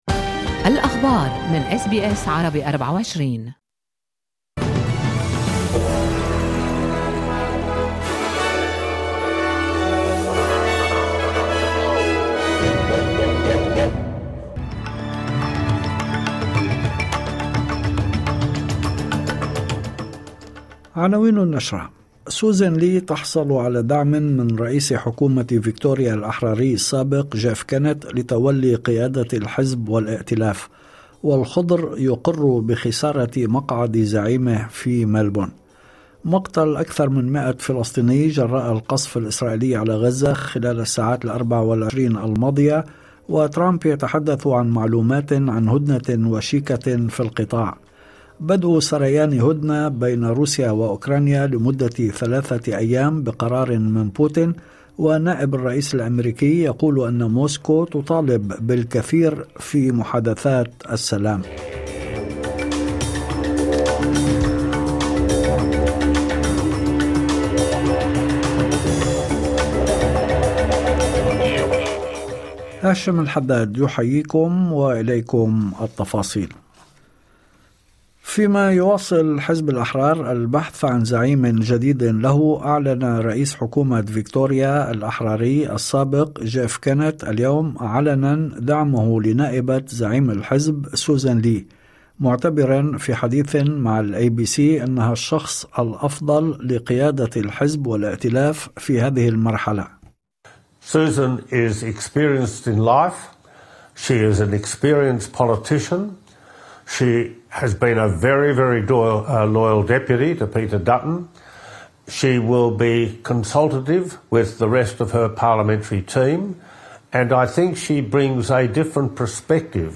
نشرة أخبار المساء 08/05/2025